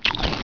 chew3.wav